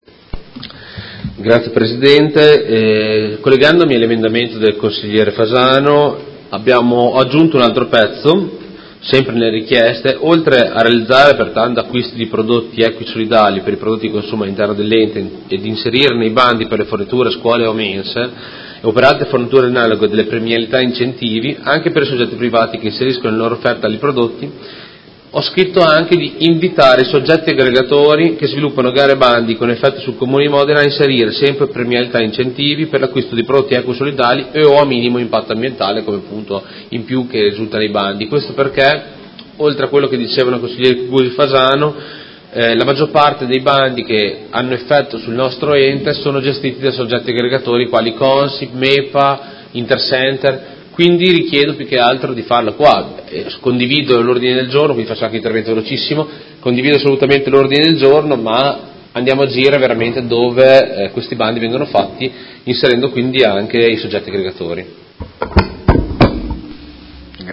Seduta del 21/03/2019 Presenta emendamento Prot. n. 75002 a Ordine del Giorno Prot. Gen. 189287 presentato dal Gruppo Articolo 1-MDP – PerMeModena avente per oggetto: Adesione alla campagna di costruzione di luoghi equi e solidali e nascita del Gruppo territoriale di sostegno – Primo firmatario Consigliere Cugusi